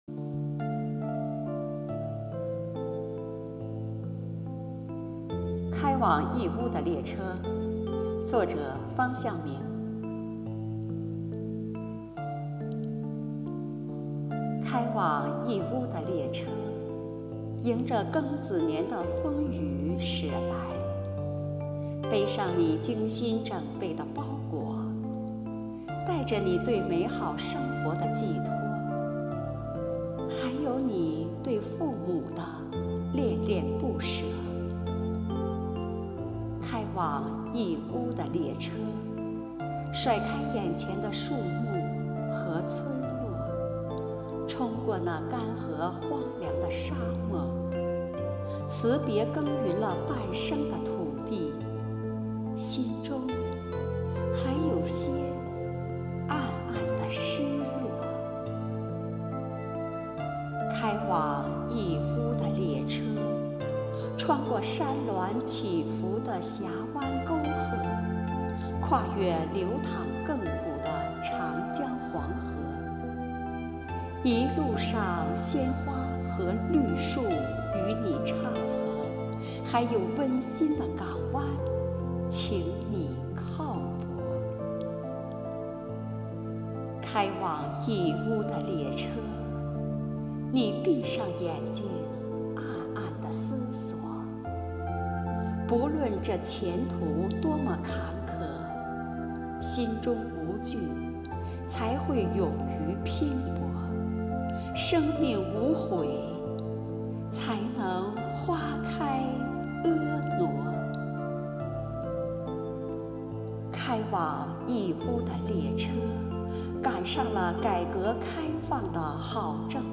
开往义乌的列车（朗诵诗）